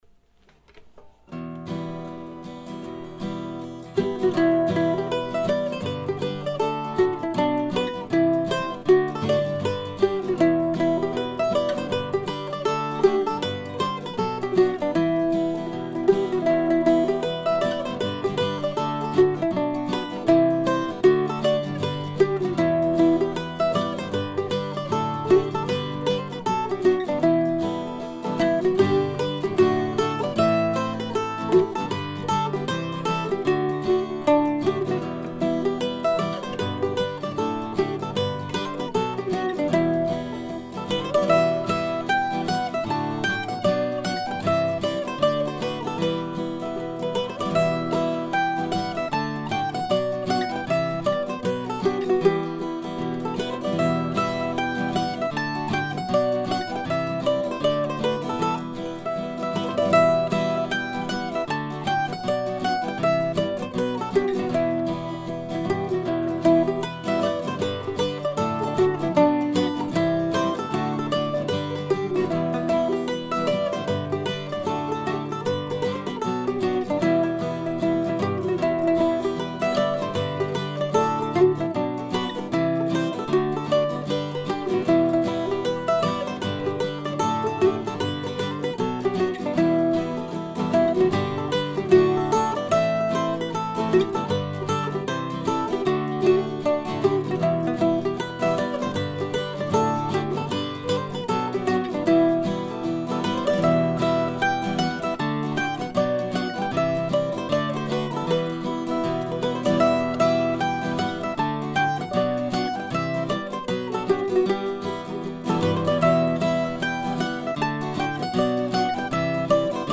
My little tune was written down over 20 years ago and is rarely played, partly because of its AABCC format.
I recorded it last night using an old Gibson mandolin that was built around 1917, not long after Jorgensen's biography of Francis was written.